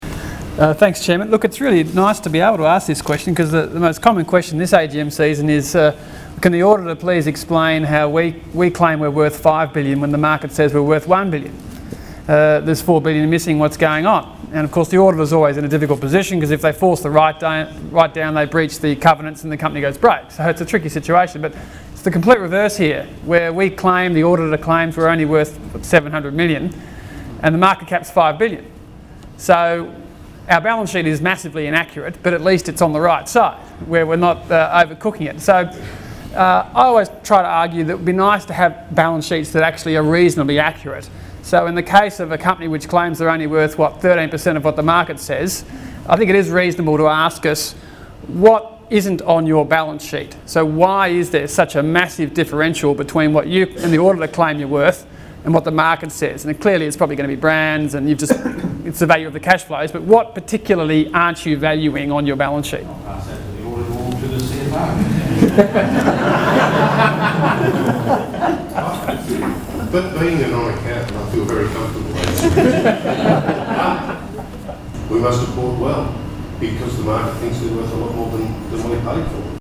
Below is a compilation of audio files from various AGMs over the years where auditors have been asked to comment on the accounts.
Computershare AGM, November 11, 2008